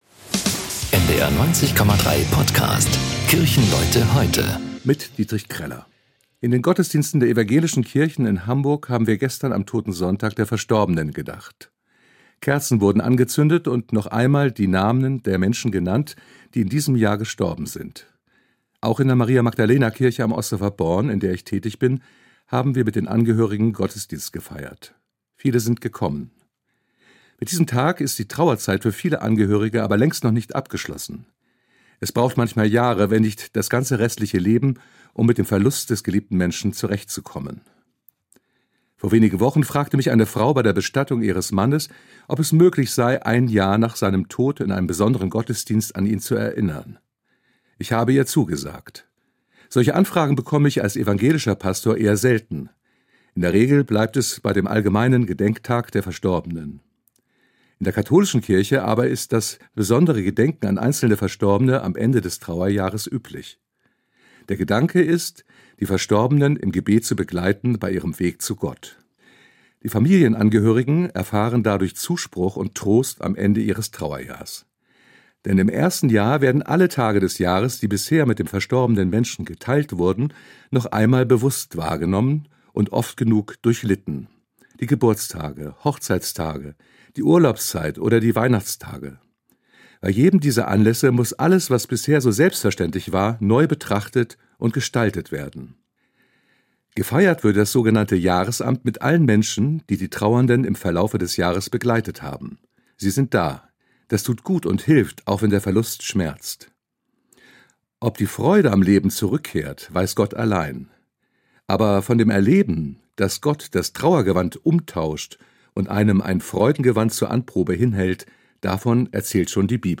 Hamburger Pastorinnen und Pastoren und andere Kirchenleute erzählen